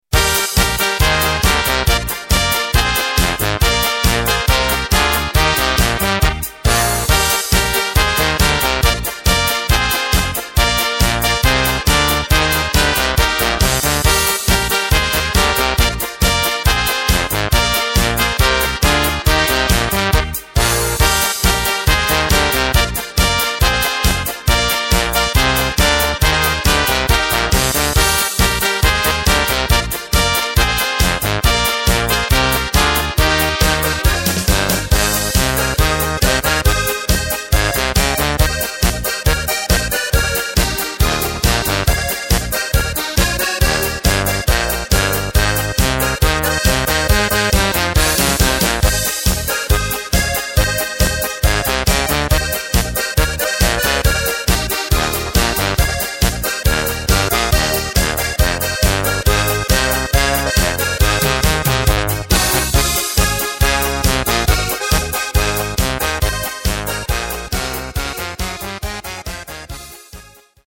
Takt:          4/4
Tempo:         138.00
Tonart:            Eb
Polka aus dem Jahr 2019!